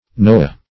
Noah \No"ah\, prop. n. [Heb. N[=o]akh rest.]